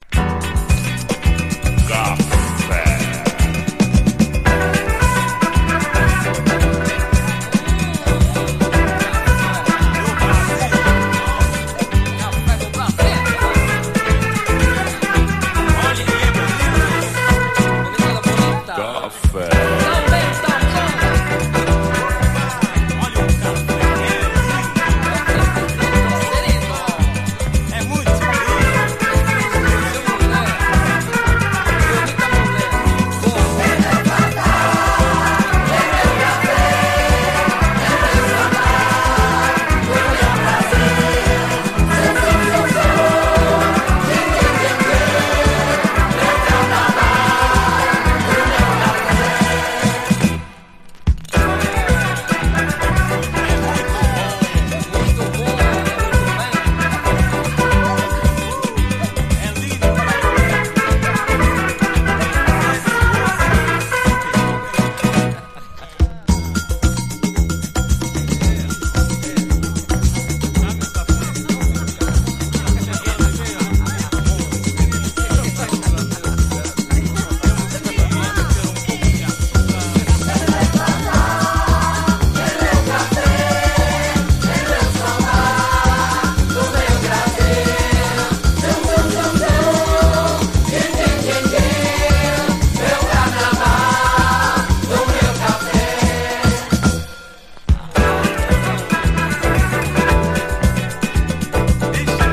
SOUL, 70's～ SOUL, DISCO, 7INCH
トボけたテイストでありながら、抜群にフロア・ユース！
B級トロピカル・ディスコの隠し玉